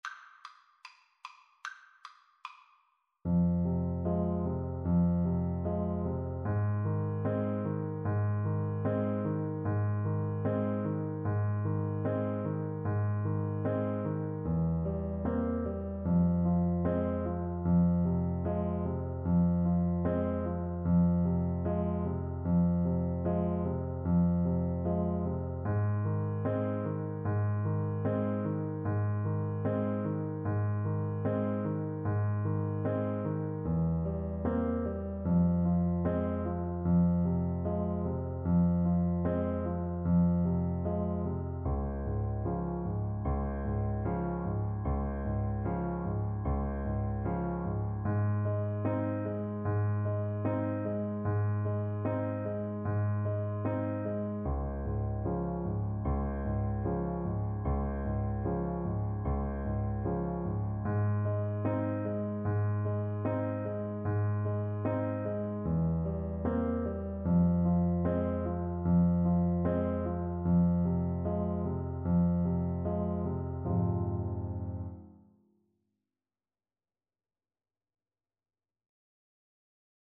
Moderato = 150
4/4 (View more 4/4 Music)
Arrangement for French Horn and Piano